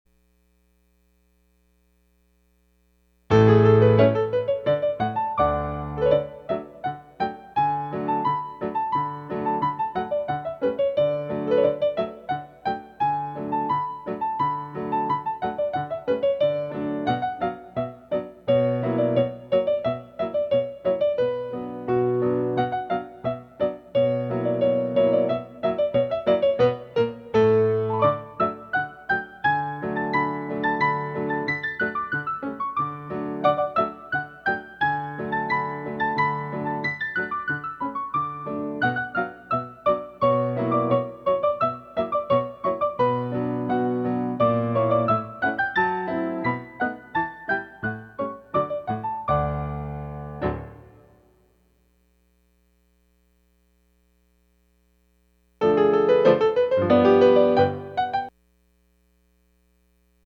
Music for Petit Allegro